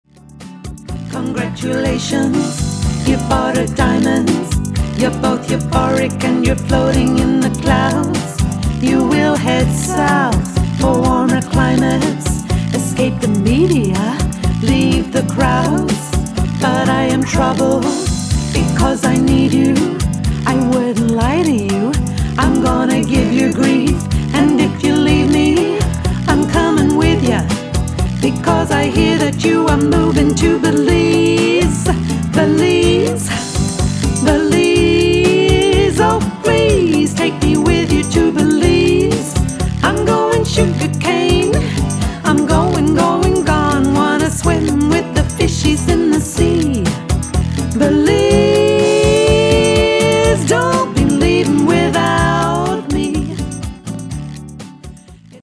Genre: Singer/Songwriter